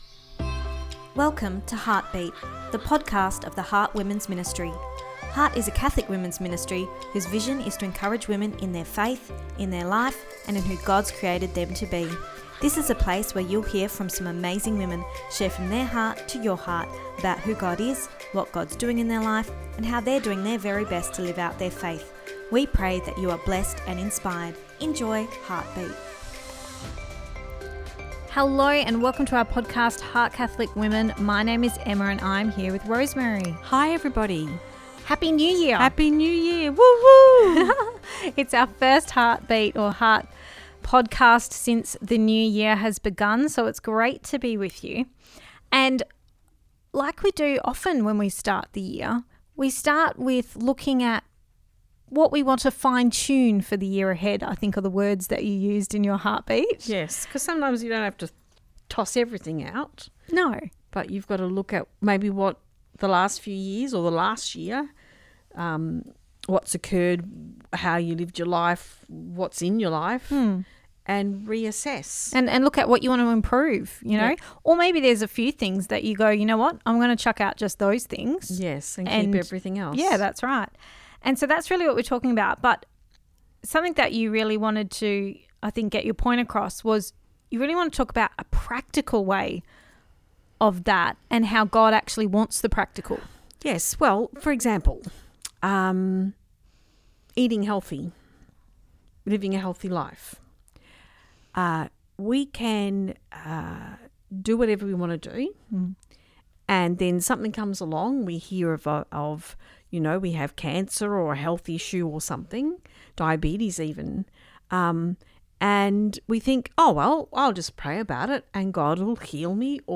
Ep229 Pt2 (Our Chat) – New Beginnings: The Practical